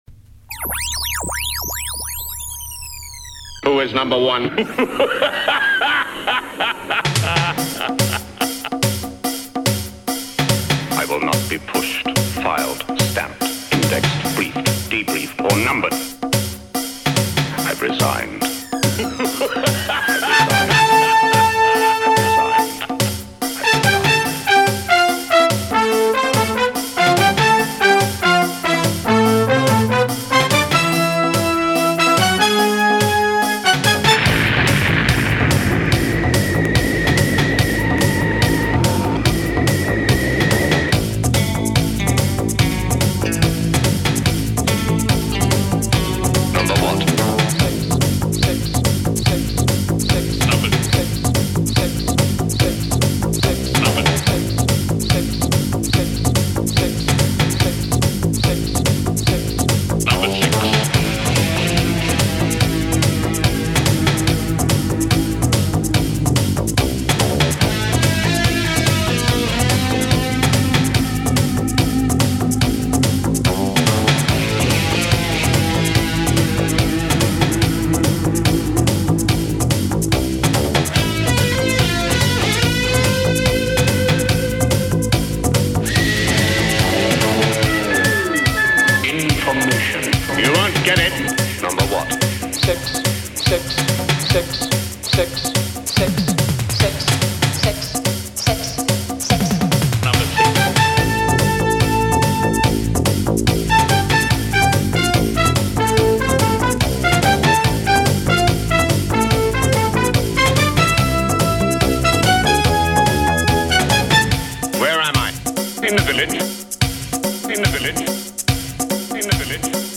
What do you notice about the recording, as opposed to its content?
So, we will continue with revised recordings for 1970 (minor edits, mainly for sound levels).